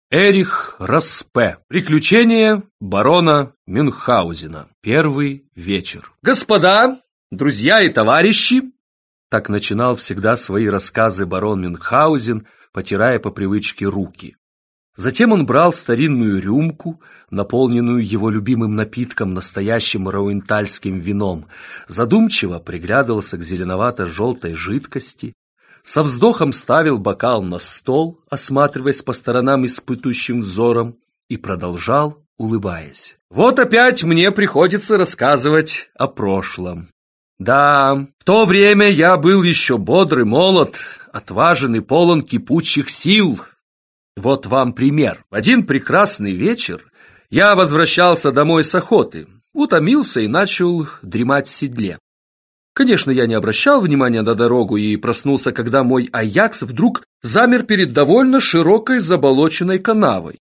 Аудиокнига Приключения барона Мюнхгаузена | Библиотека аудиокниг